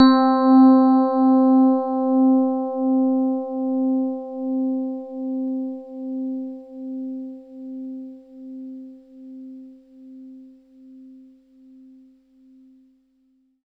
85 RHODES -L.wav